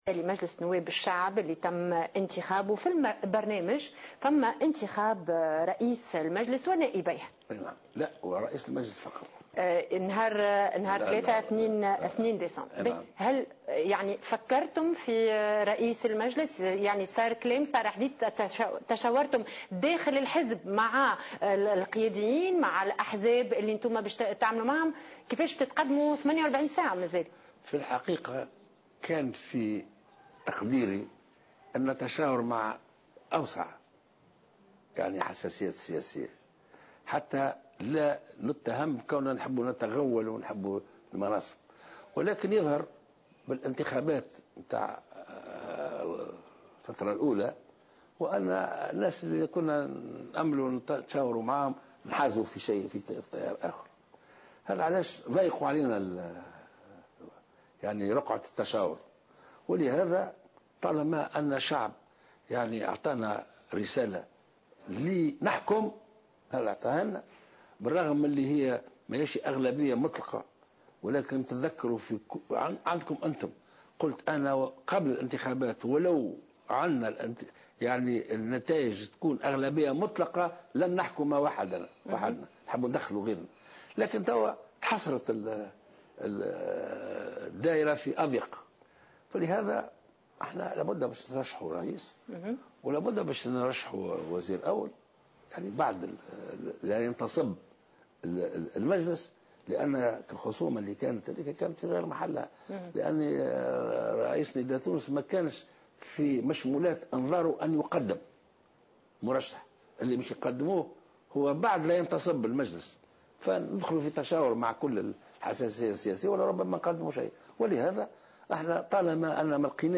أكد رئيس حركة نداء تونس الباجي قايد السبسي في حوار تلفزي على قناة نسمة بثته مساء اليوم الأحد 30 نوفمبر 2014 إنه سيقدم الثلاثاء القادم خلال الجلسة الإفتتاحية لمجلس الشعب اسم رئيس المجلس واسم الوزير الاول طالما انتخبه الشعب من أجل هذا .